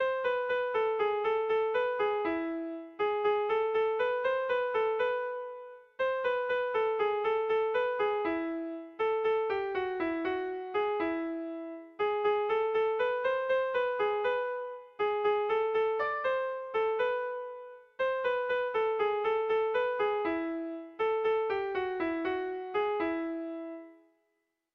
Kontakizunezkoa
Zortziko handia (hg) / Lau puntuko handia (ip)
A1-A2-B-A2